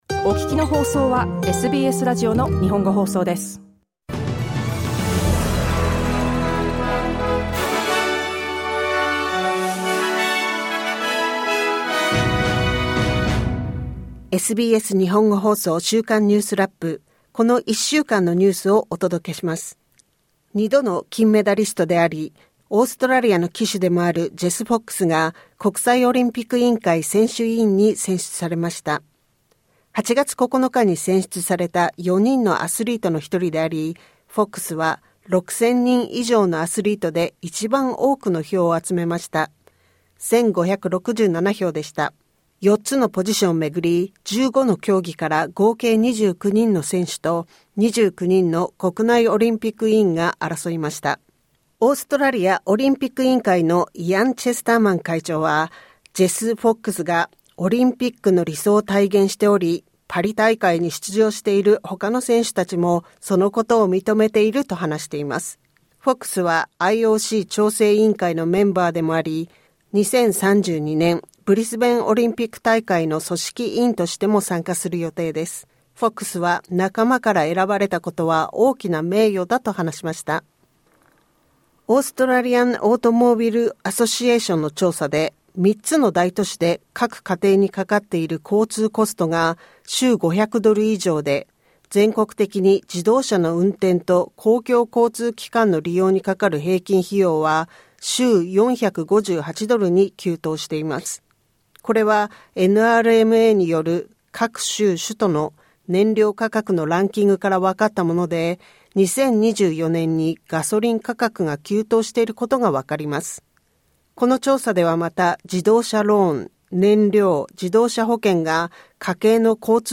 2度の金メダリストであり、オーストラリアの旗手でもあるジェス・フォックスが、国際オリンピック委員会選手委員に選出されました。自然災害に関する上院の調査委員会で、保険会社がクイーンズランド州北部のサイクロン被害地域への支援に十分な対応をしていないことが明らかになる予定です。ウィーンで行われる予定だった、Taylor Swift のコンサート3公演が、主催者によって中止となりました。その他、1週間を振り返るニュースラップです。